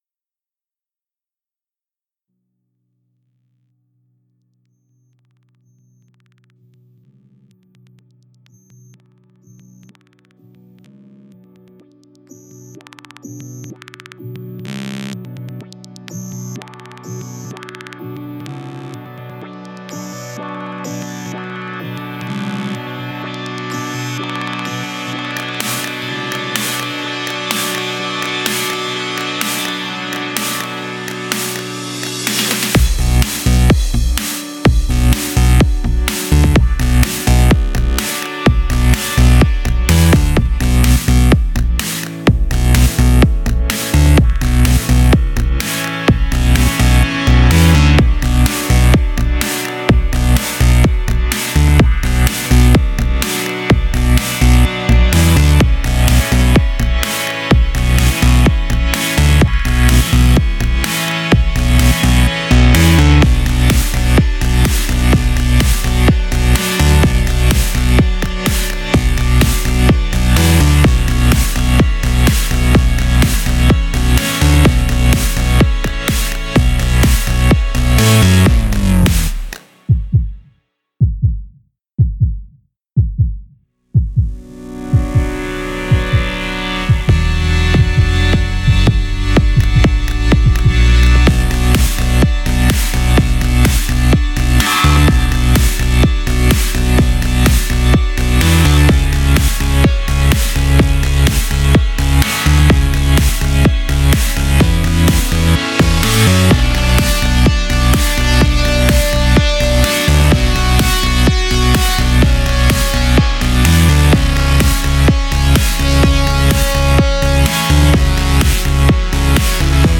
"Epischer" Club - Track (mal was anderes von mir)